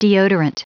Prononciation du mot deodorant en anglais (fichier audio)
Prononciation du mot : deodorant